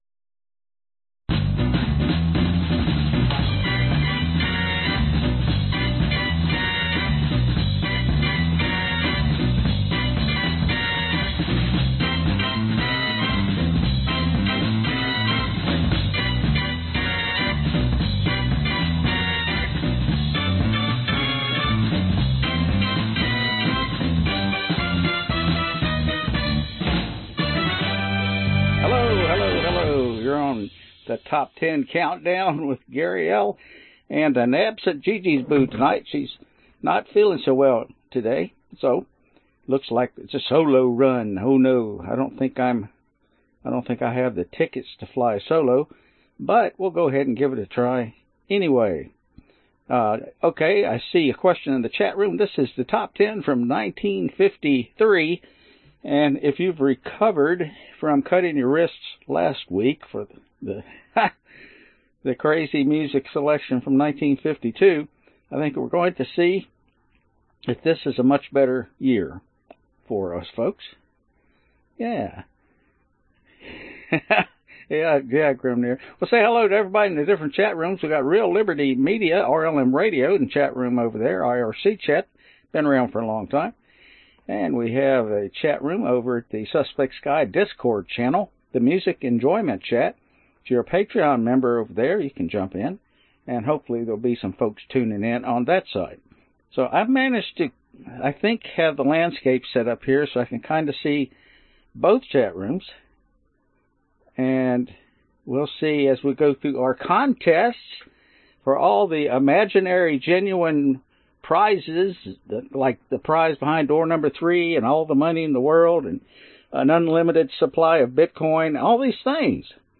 Top Ten Countdown - 1953 Genre Oldies